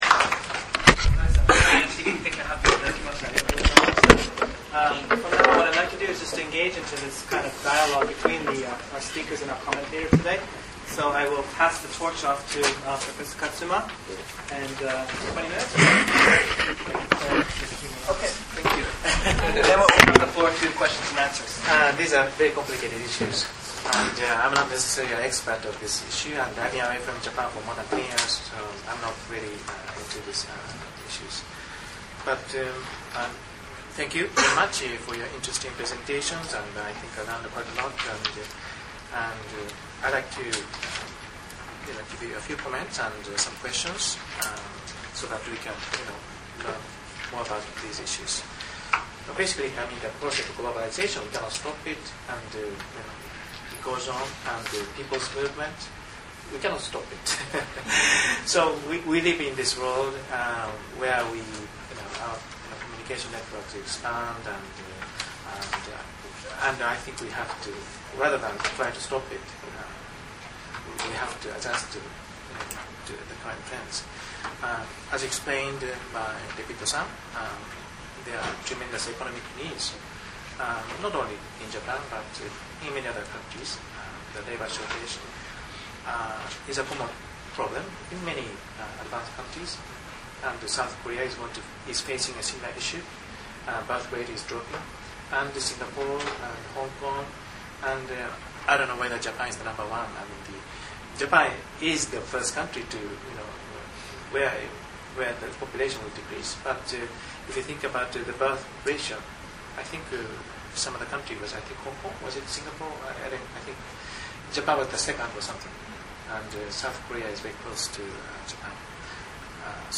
Part One offers the first 25 minutes of the proceedings (the first couple of minutes were cut off), with my presentation. I talk about how Japan has brought in foreign laborers for economic reasons and not taken care of them.
The sound quality is as good as we can make it.